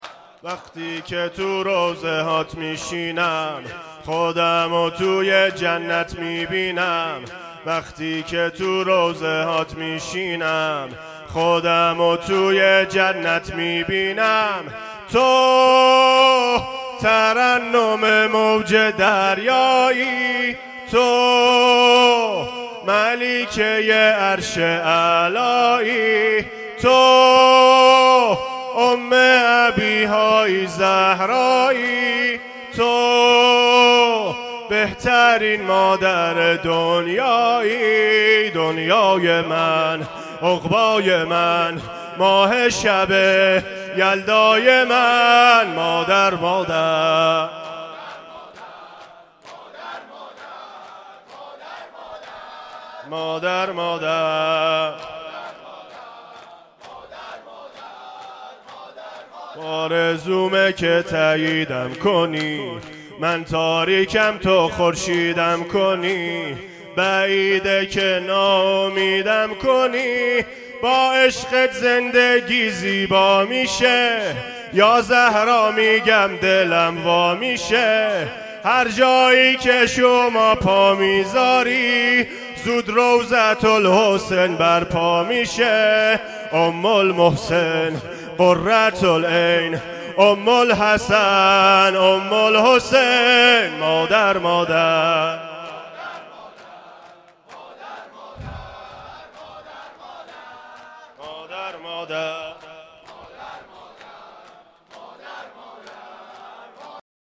شور یک شب اول فاطمیه
مداحی